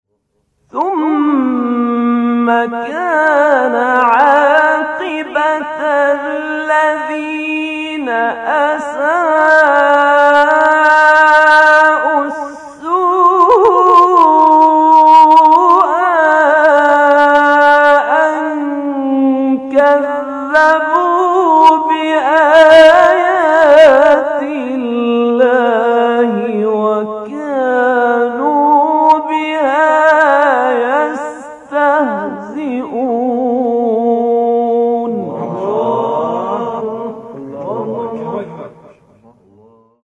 صوت | تلاوت آیه 10«سوره روم» با صوت قاریان شهیر